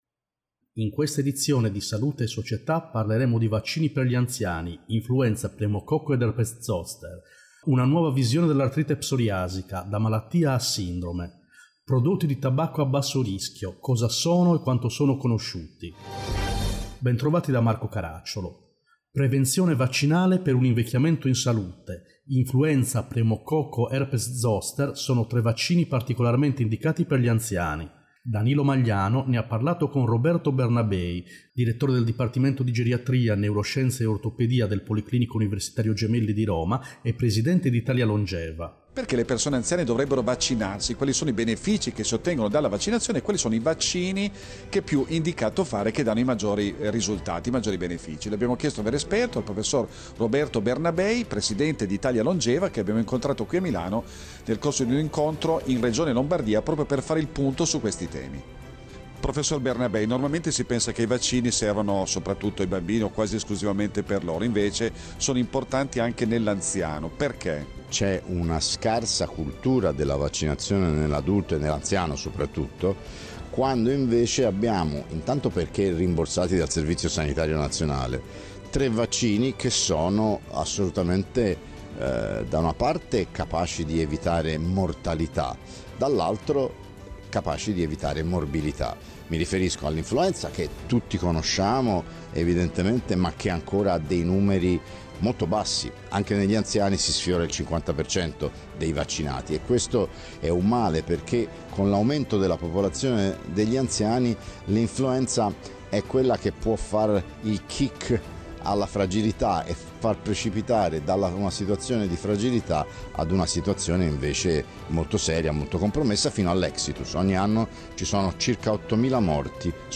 In questa edizione: Tre vaccini per gli anziani, Influenza, pneumococco, herpes zoster Nuova visione dell’artrite psoriasica, da malattia a sindrome Prodotti di tabacco a basso rischio, cosa sono e quanto sono conosciuti Interviste